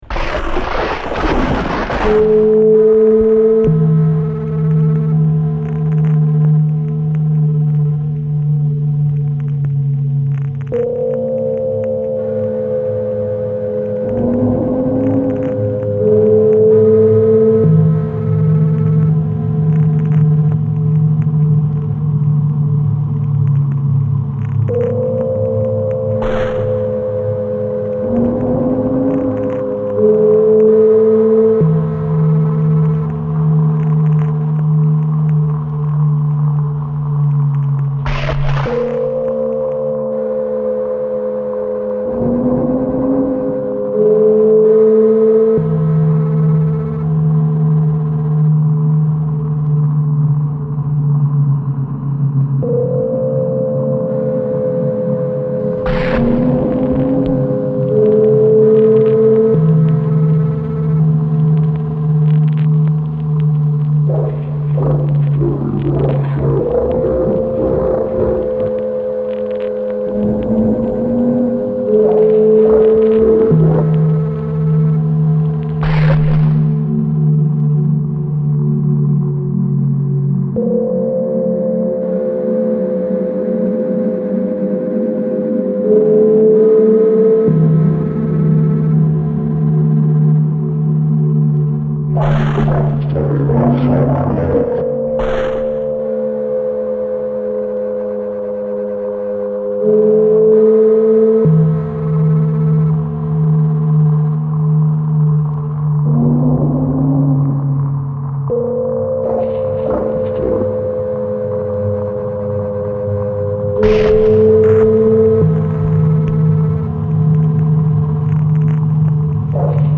Creator's Tags: Audio Collage